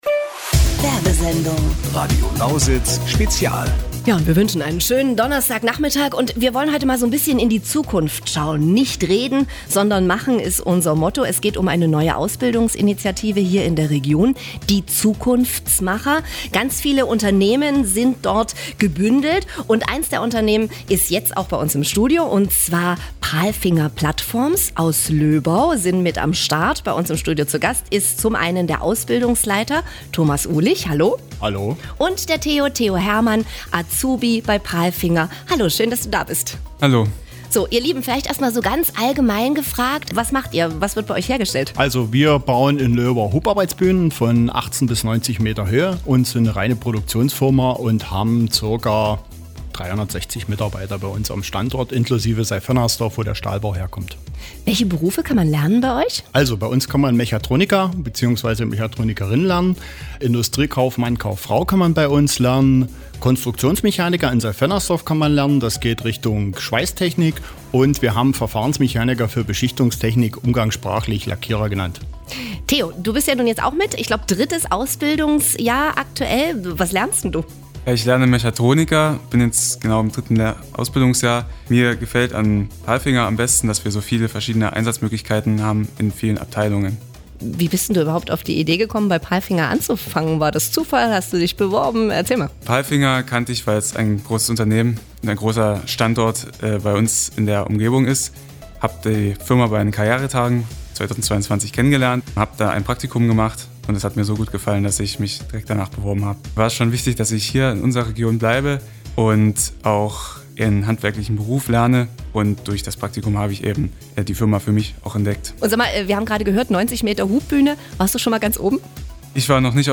Das Ausbildungscluster Zukunftsmacher war am 26. September zu Gast in einer Sondersendung bei Radio Lausitz.
Palfinger Platforms im Interview: